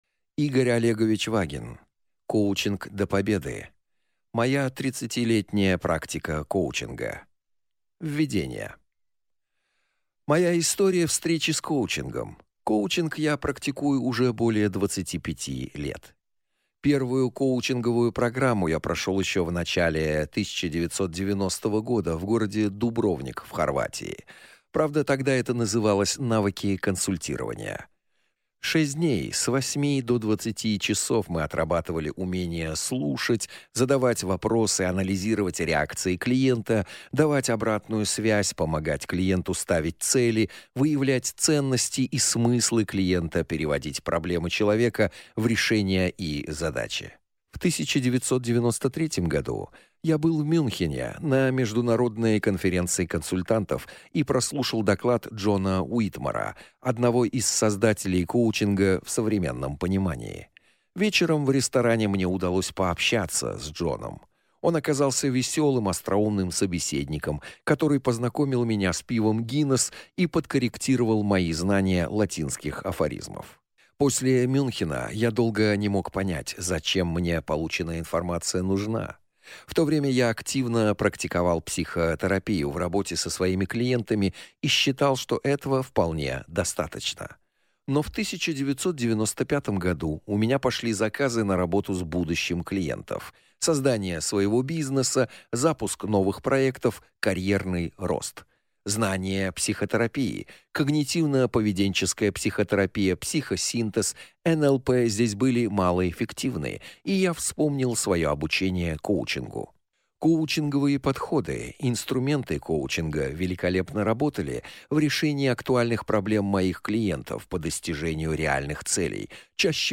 Аудиокнига Коучинг до победы. Моя 30-летняя практика коучинга | Библиотека аудиокниг